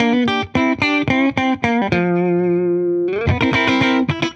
Index of /musicradar/dusty-funk-samples/Guitar/110bpm
DF_70sStrat_110-G.wav